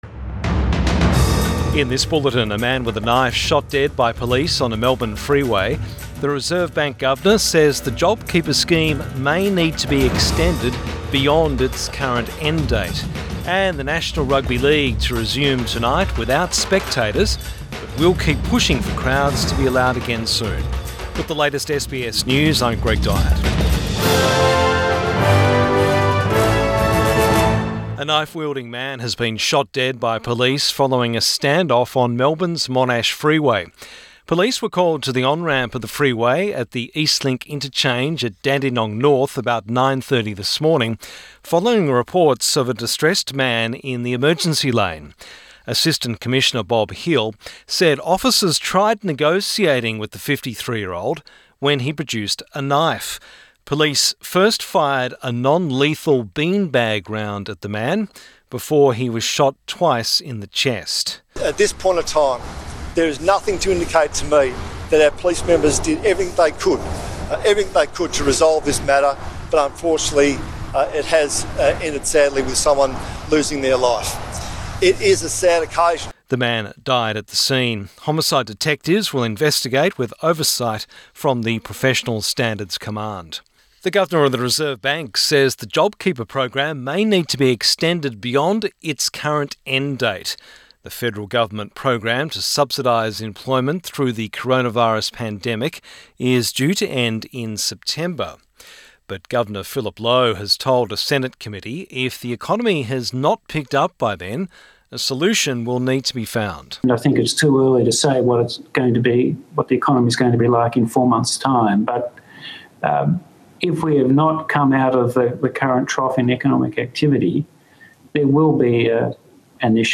PM bulletin 28 May 2020